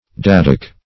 Search Result for " daddock" : The Collaborative International Dictionary of English v.0.48: Daddock \Dad"dock\, n. [Cf. Prov.